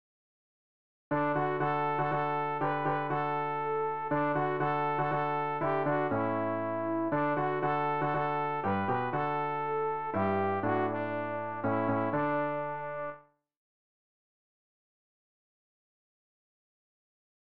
rg-702-kum-ba-yah-sopran.mp3